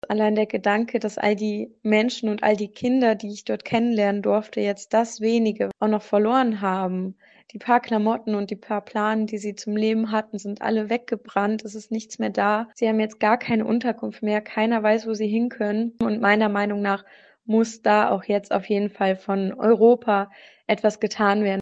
Die junge Studentin ist entsetzt über den Brand des Flüchtlingslagers: